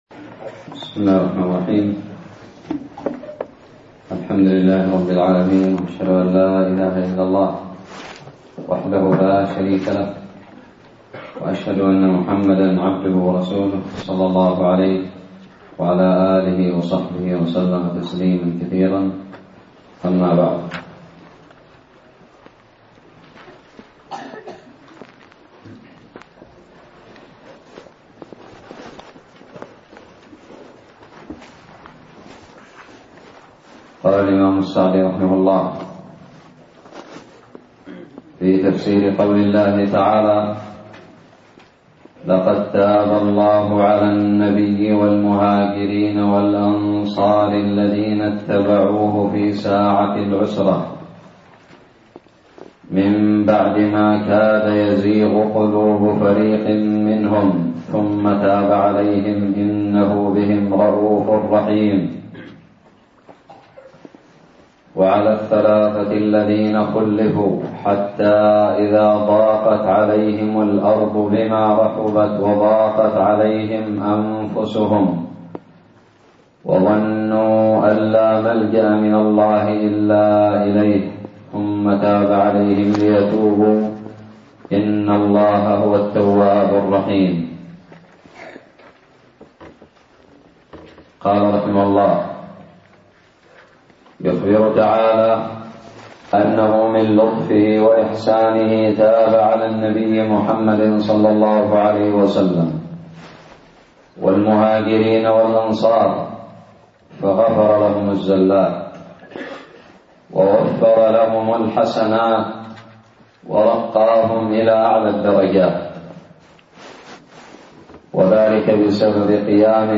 الدرس الحادي والخمسون من تفسير سورة التوبة
ألقيت بدار الحديث السلفية للعلوم الشرعية بالضالع